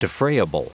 Prononciation du mot defrayable en anglais (fichier audio)
Prononciation du mot : defrayable